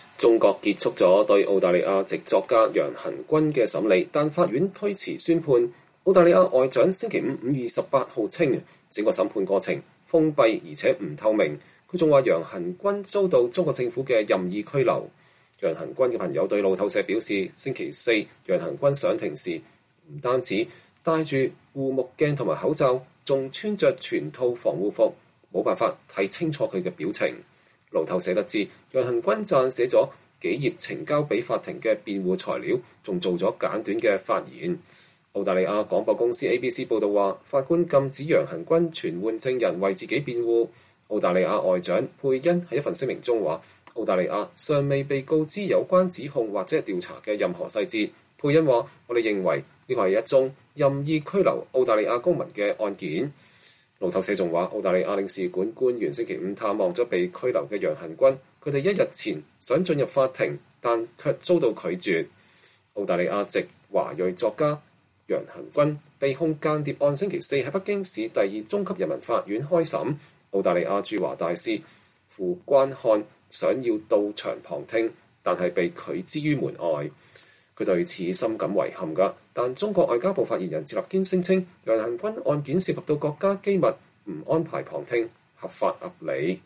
澳大利亞駐華大使傅關漢(Graham Fletcher) 在不准許進入審理楊恆均案的北京法庭後對記者講話。（2021年5月27日）